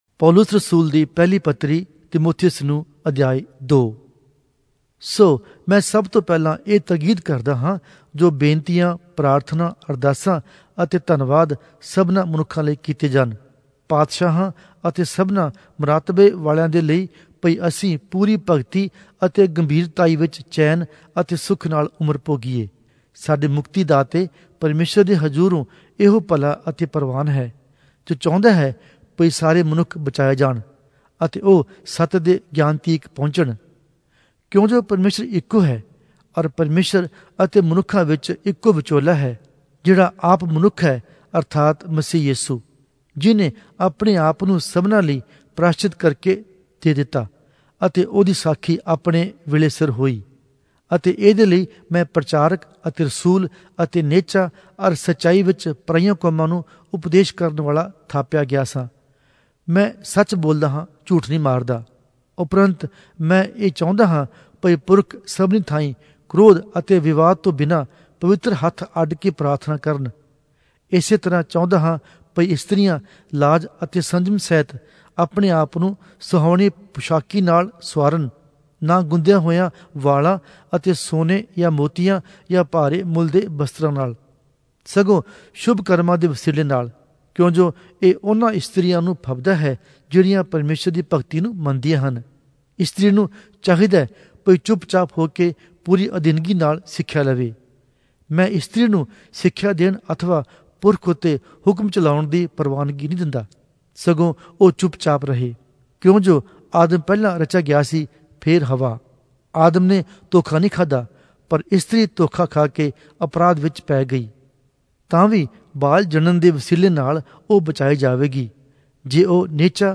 Punjabi Audio Bible - 1-Timothy 2 in Knv bible version